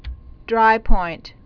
(drīpoint)